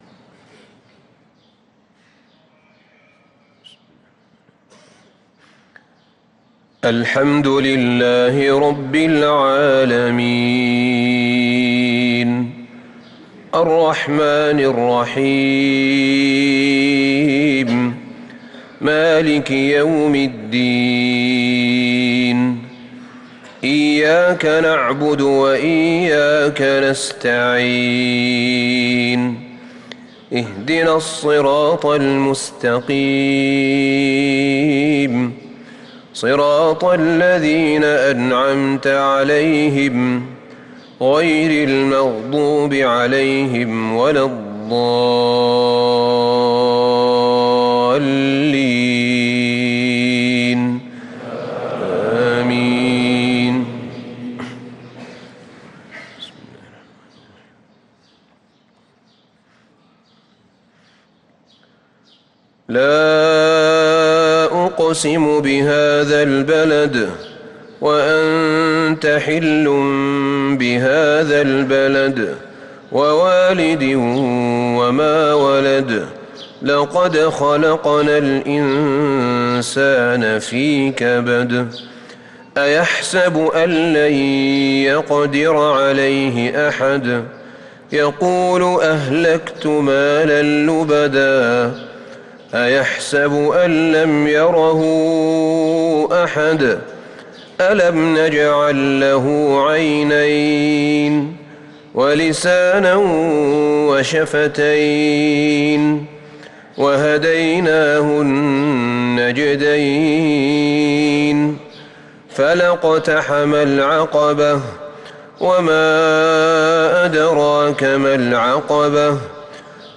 مغرب الأربعاء 9-8-1444هـ سورتي البلد و الماعون | Maghreb prayer from surah al-Balad & Al-Alma’uon 1-3-2023 > 1444 🕌 > الفروض - تلاوات الحرمين